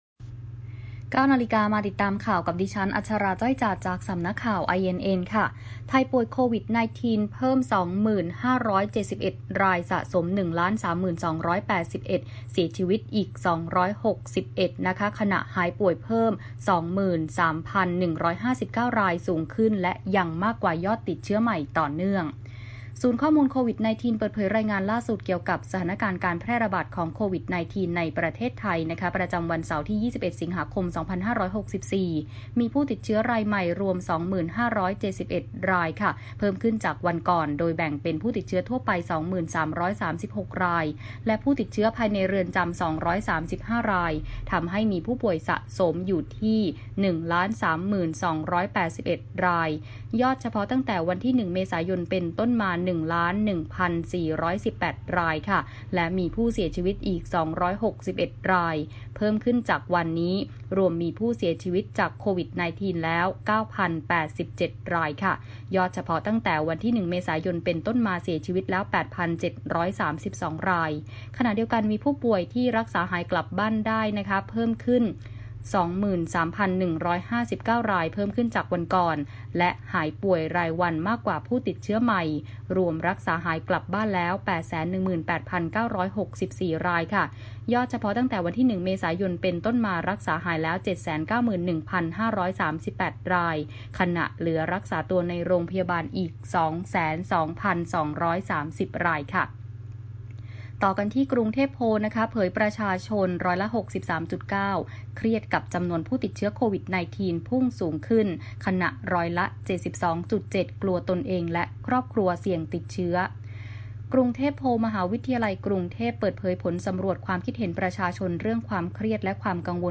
ข่าวต้นชั่วโมง 09.00 น.